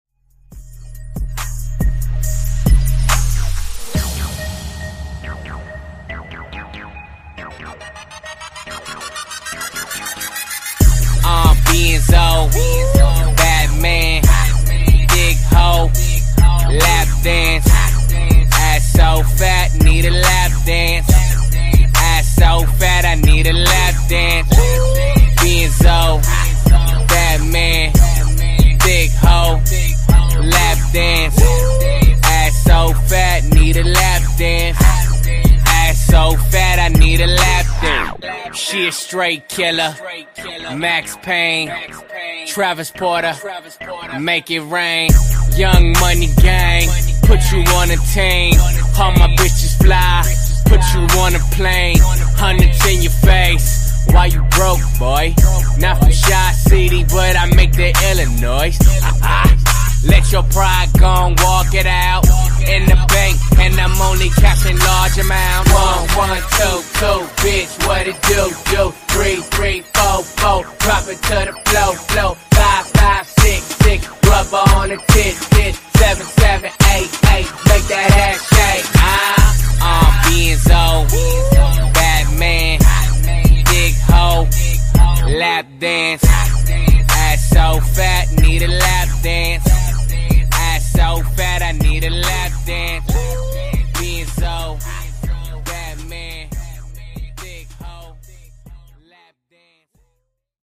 Genres: RE-DRUM , REGGAETON
Clean BPM: 110 Time